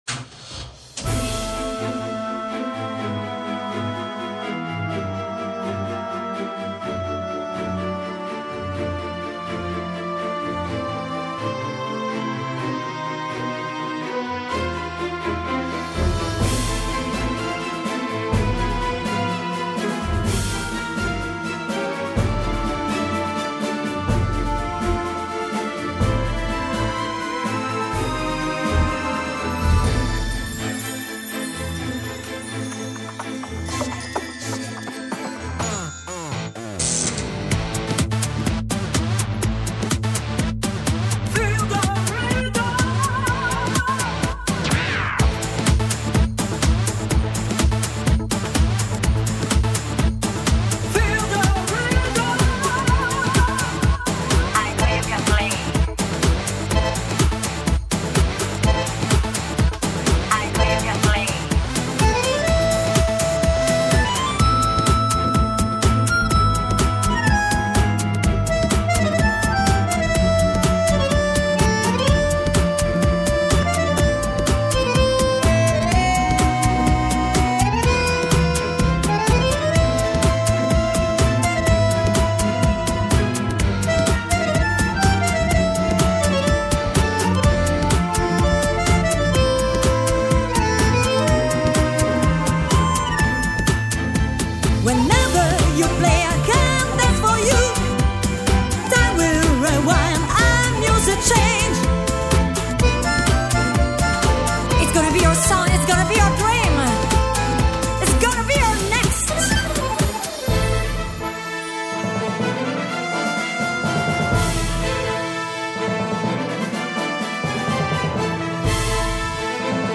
accordionist
FR-1 Promotion-Show
Text of the FR-1 Promotional Song